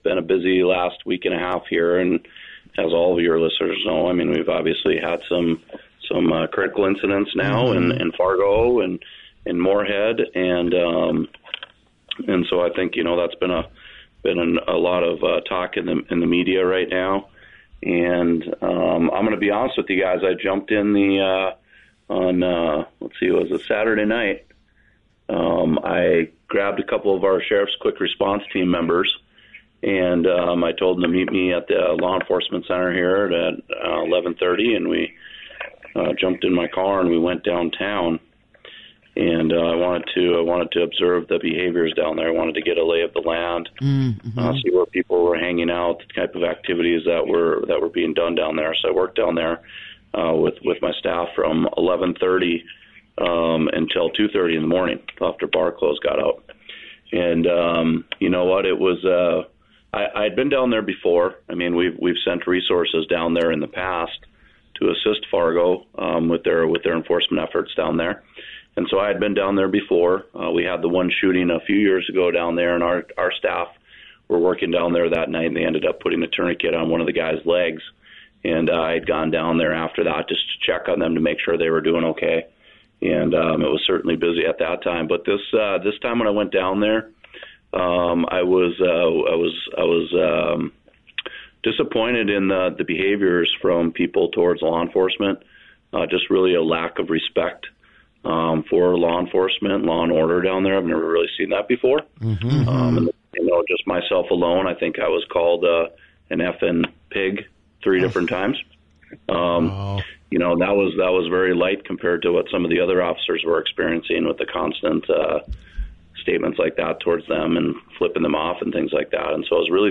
During an interview on WDAY’s The Coffee Club, Sheriff Jesse Jahner says he and his fellow law enforcement members weathered a tirade of heckling while observing city policing on Saturday August 9th.